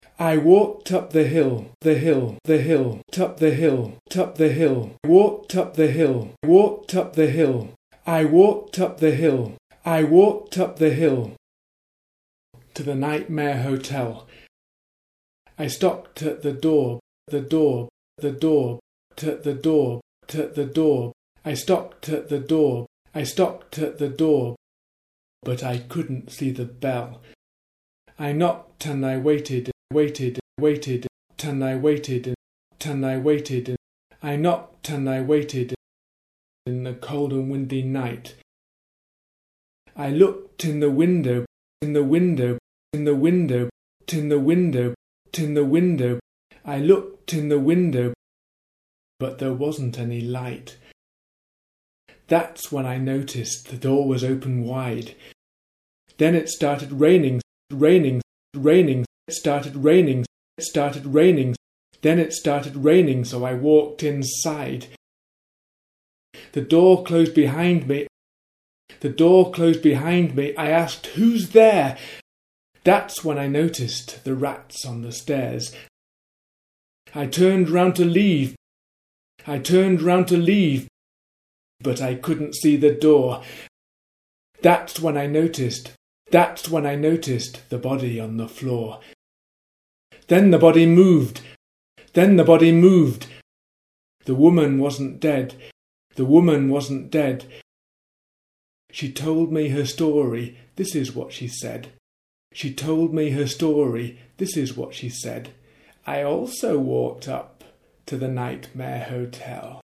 Nightmare Hotel pronunciation.mp3